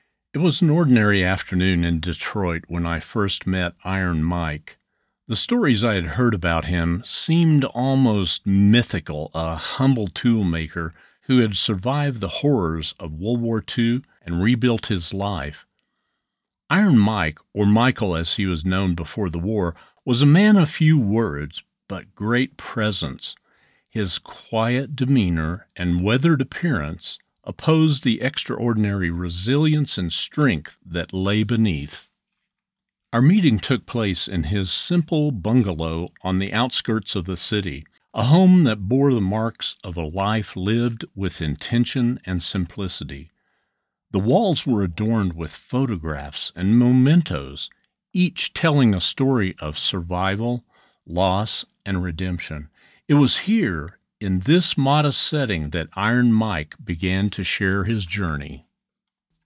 Documentary
English (North American)
US midwest, southern, announcer, meditation, engaging, inspirational, soothing and warm
My in home studio is ACX certified to meet industry standards.